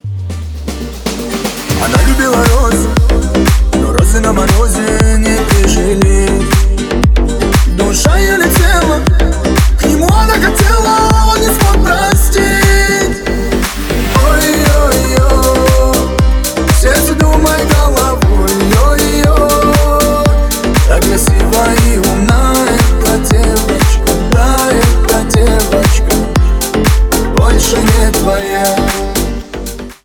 Ремикс
кавказские # клубные